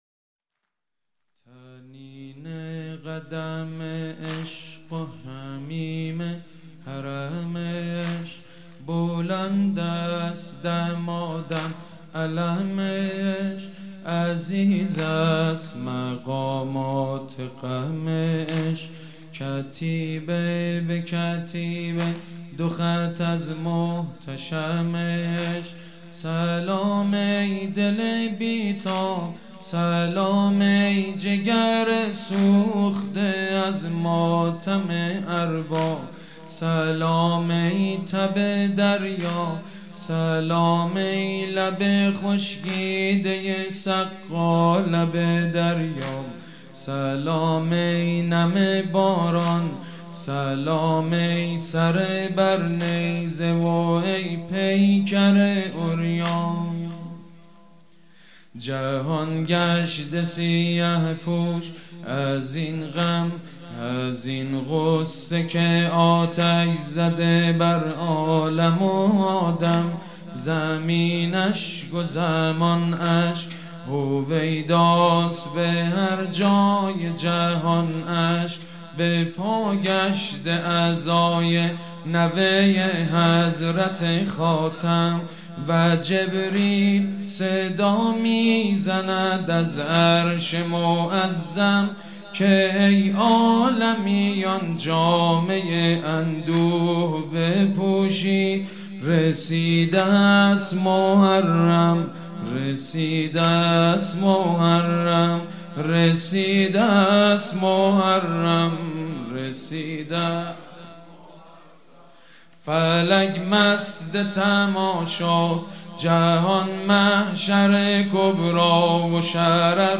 مداحی جدید
شب اول محرم 1399 هیات ریحانة الحسین(س)